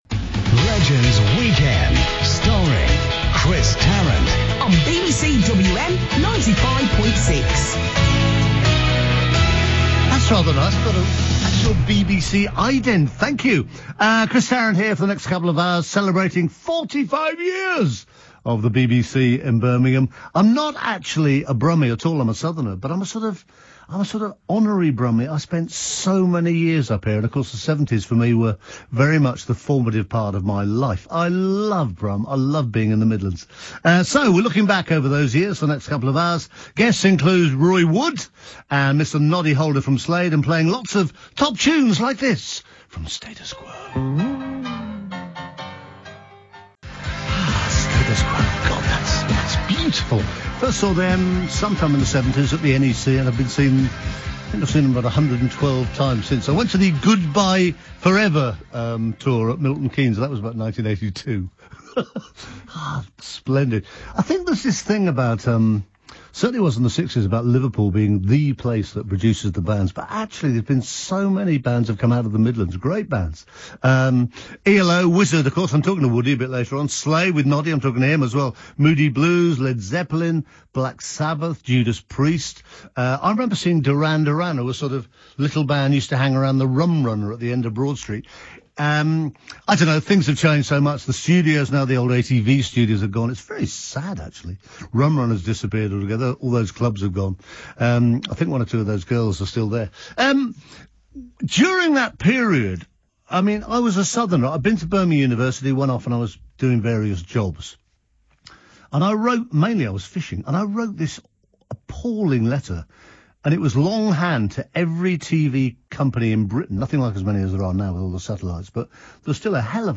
Enjoy here some great reminiscences of his life in a return to his old stomping ground as he hosted a 45th anniversary programme on BBC WM